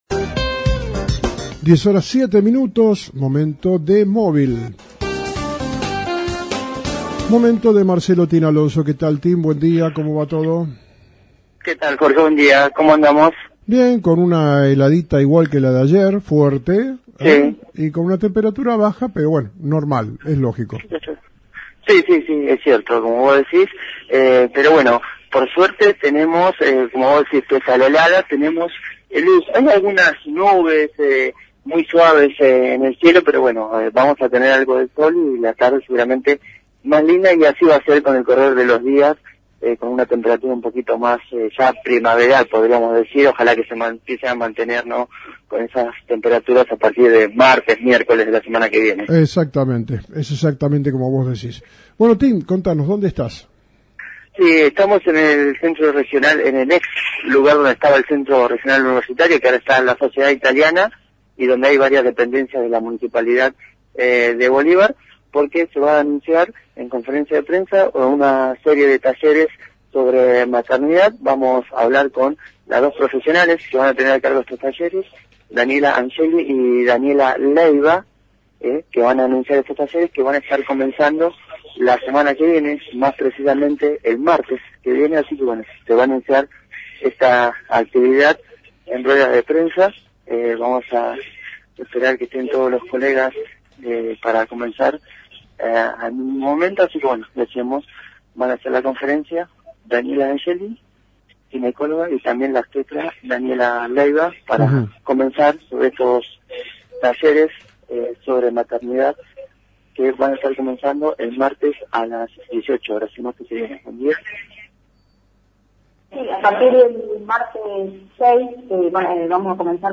Conferencia de Prensa